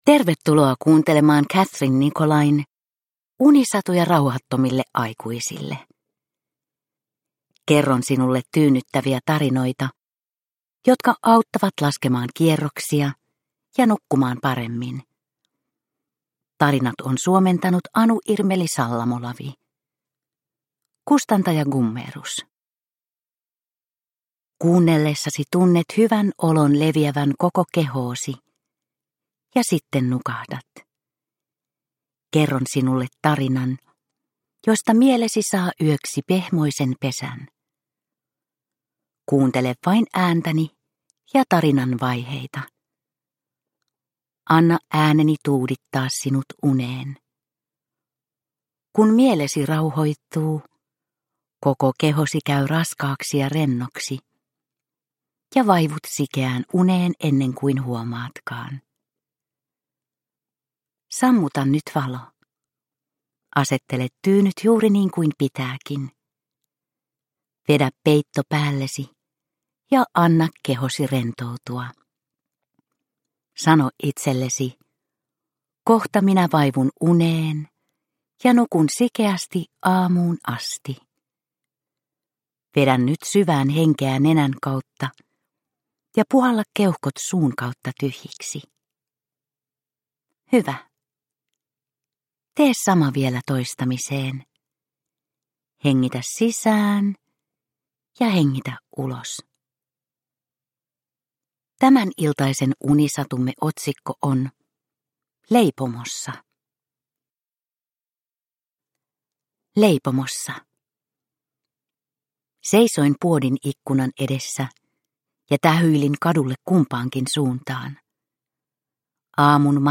Unisatuja rauhattomille aikuisille 30 - Leipomossa – Ljudbok – Laddas ner